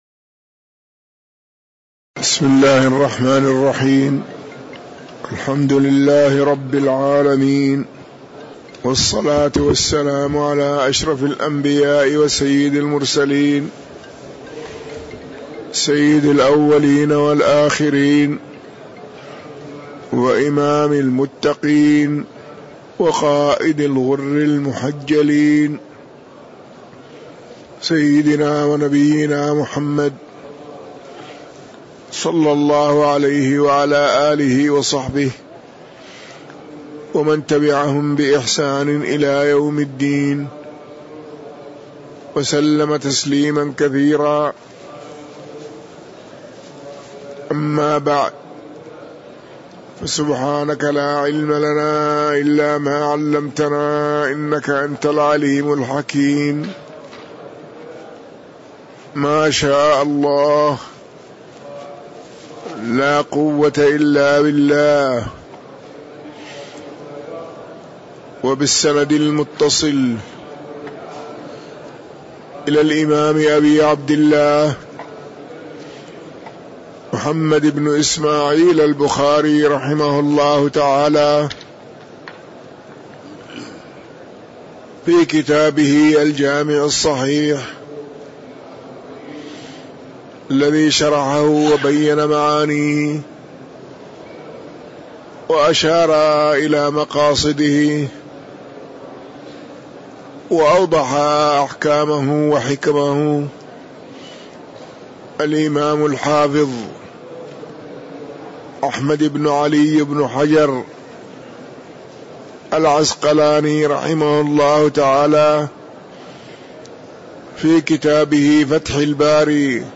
تاريخ النشر ٣ شعبان ١٤٤٣ هـ المكان: المسجد النبوي الشيخ